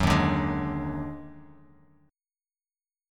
D#mM11 chord